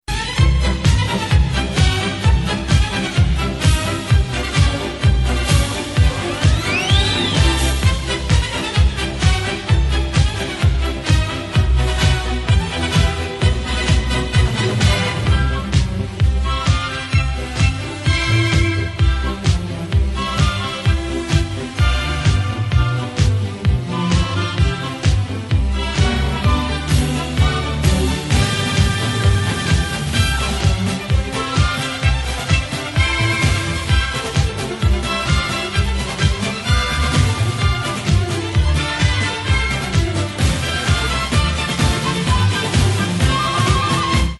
Цирковая мелодия